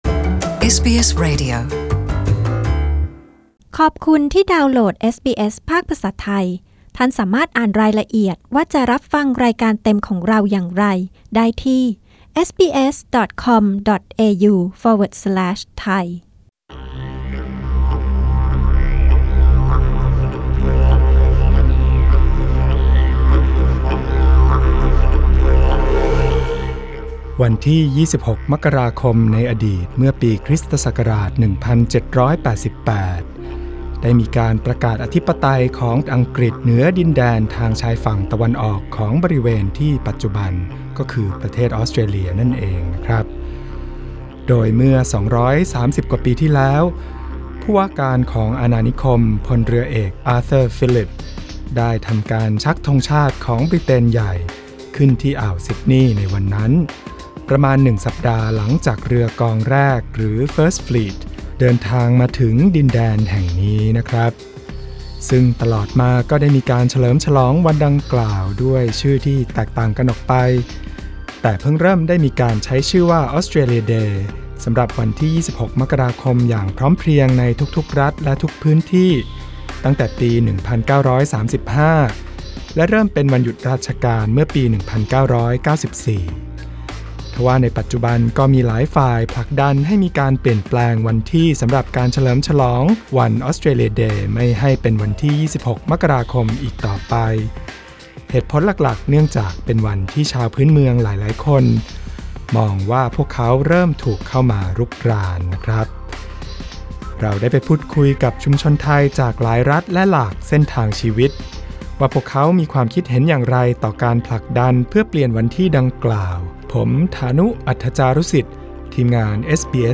เอสบีเอส ไทย พูดคุยกับชุมชนไทยจากหลายรัฐและหลากเส้นทางชีวิต ว่าพวกเขามีความคิดเห็นอย่างไรต่อการผลักดันเพื่อให้วัน “ออสเตรเลียเดย์” เฉลิมฉลองกันในวันที่อื่นซึ่งไม่ใช่วันที่ 26 มกราคม